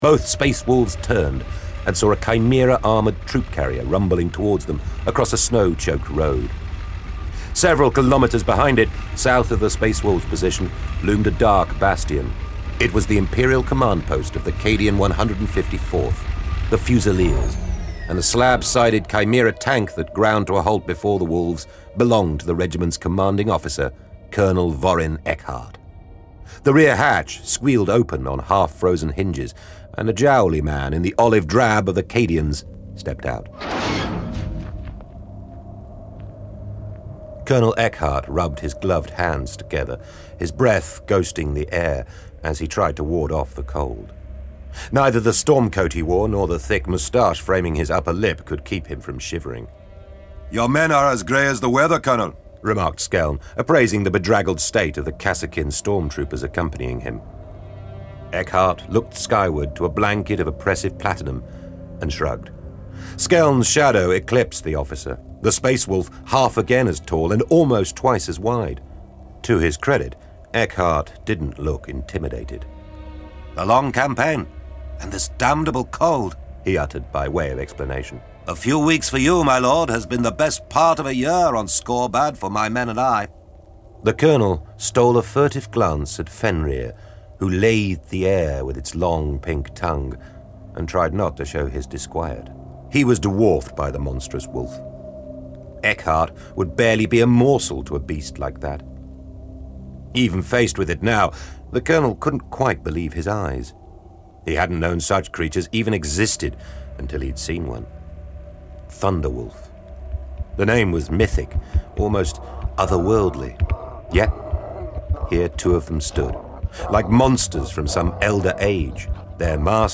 Index of /Games/MothTrove/Black Library/Warhammer 40,000/Audiobooks/Thunder from Fenris